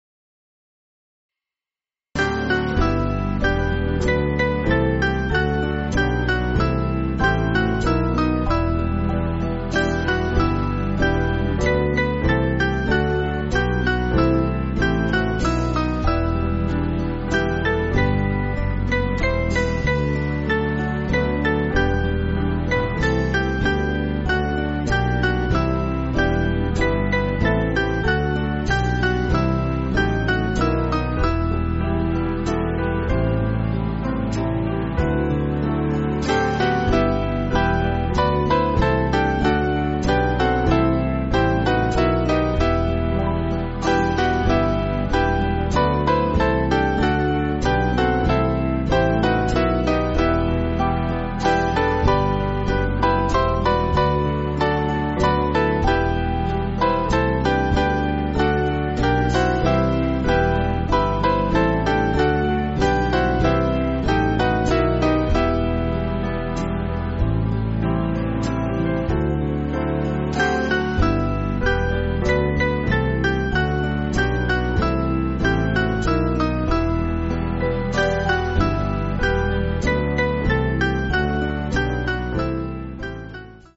Small Band
(CM)   4/Em